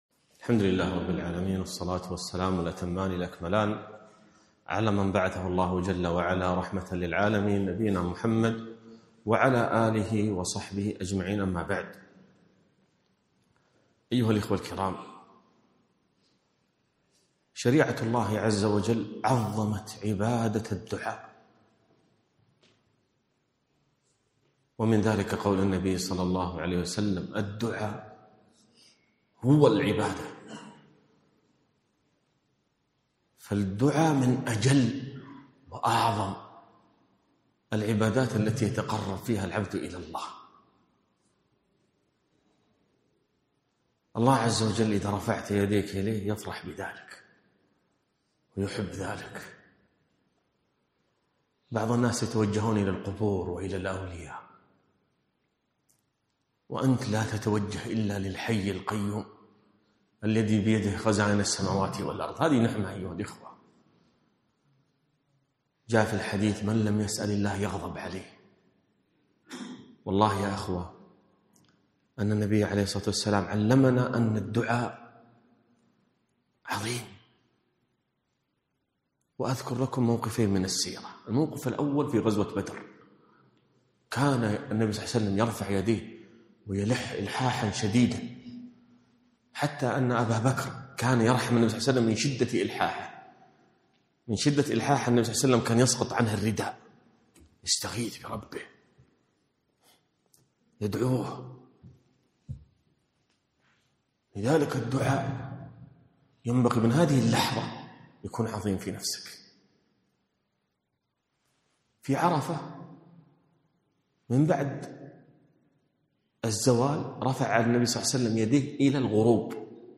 محاضرة - من أدعية المصطفى ﷺ